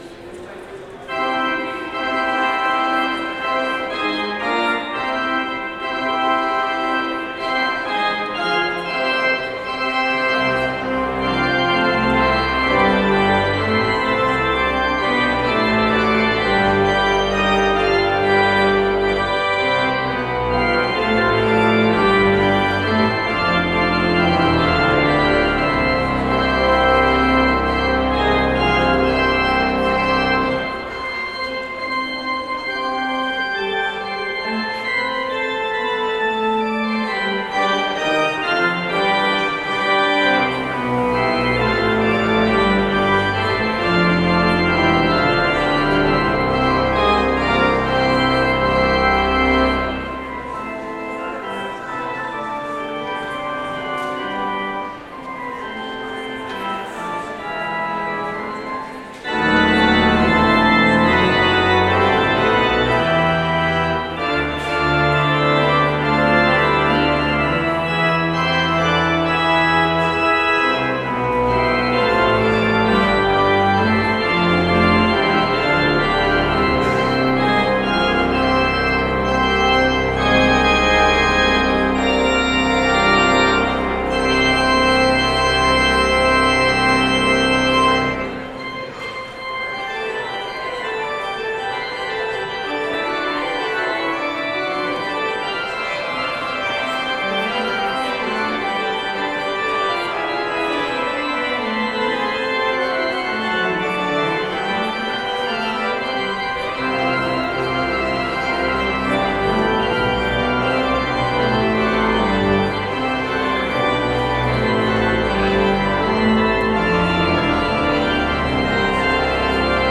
Prelude - A Mighty Fortress
organ